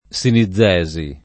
[ S ini zz$@ i ]